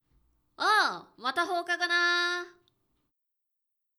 ボイス
ダウンロード 中性_「おう！また放課後なー」
中性挨拶